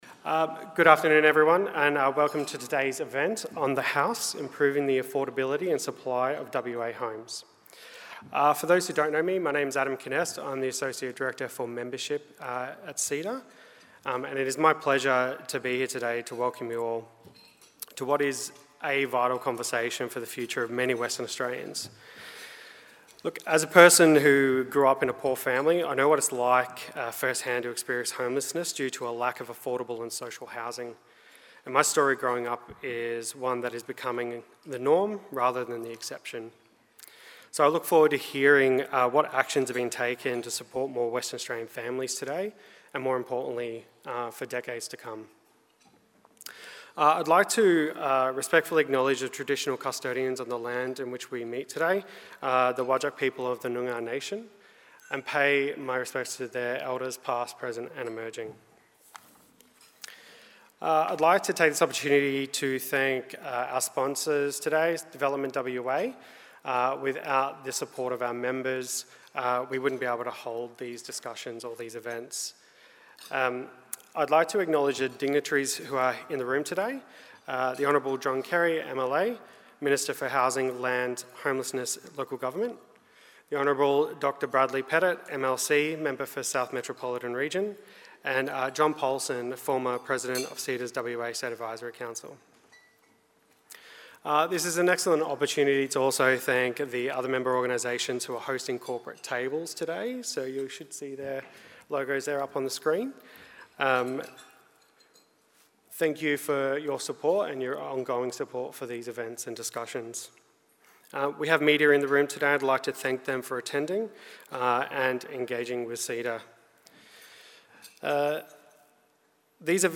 Keynote speaker The Hon. John Carey MP3
onthehouse_keynote.mp3